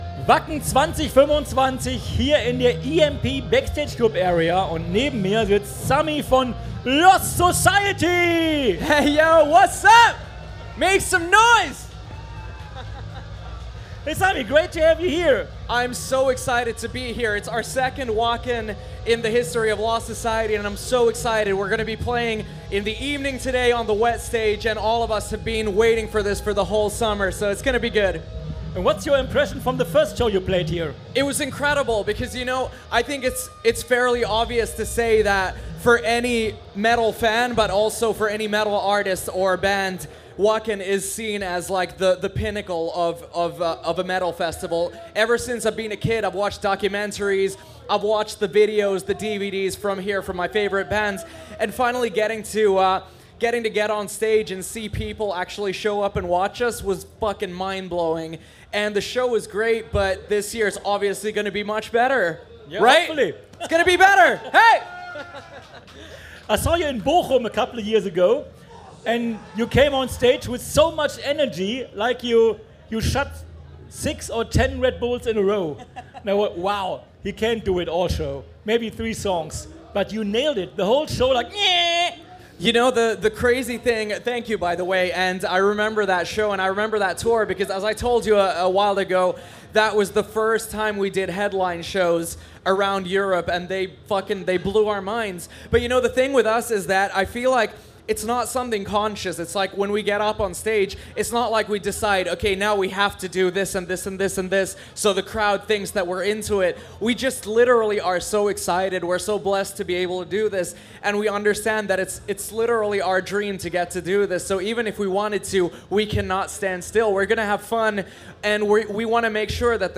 Wacken 2025 Special - Lost Society - Live aus der EMP Backstage Club Area